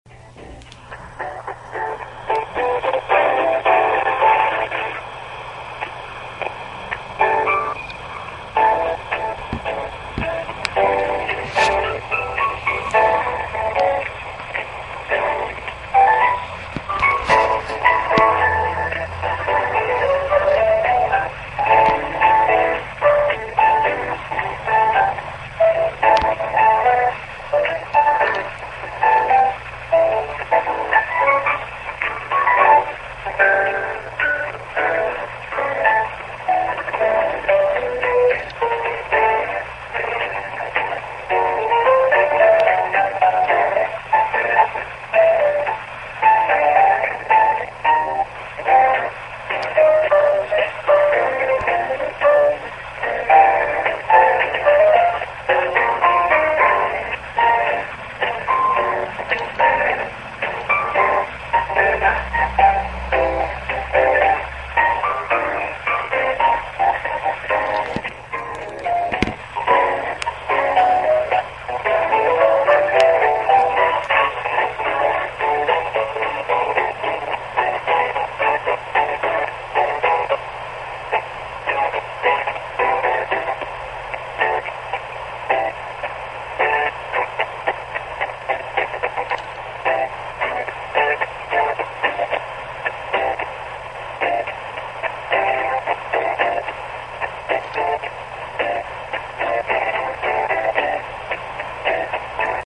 The music continues, but loud wooshing static sound slowly taking over.
13:00: That goddamn piano/white-noise music again. By now I have the cellphone on speakerphone-mode.
Toward the end of the recording you get a good idea of what the choppiness and hiss sounds like.
13:08. Still listening to the choppy hissy piano pop from hell.
vonage-torture.mp3